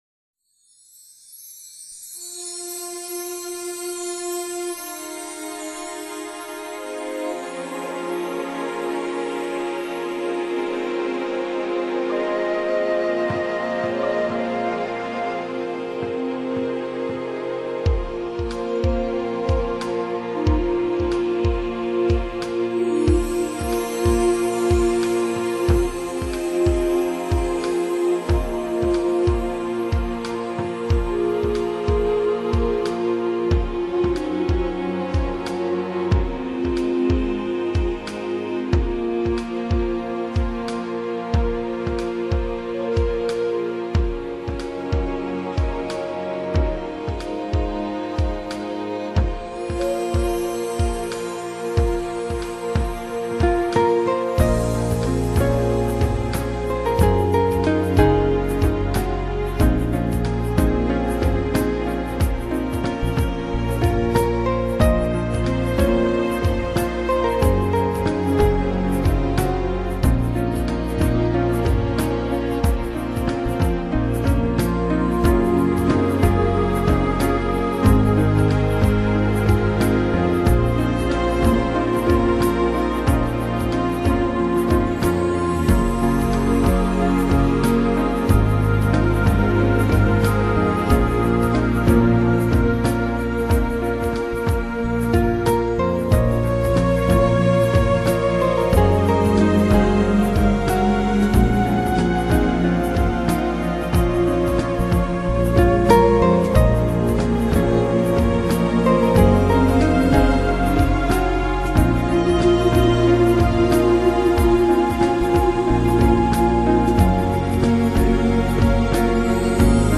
音乐类型: Newage
铜锣的振响，唤起女人的相思。指尖划过钢琴，弹起了深深的思念。
弦乐的扭捏，述说着女人的羞涩。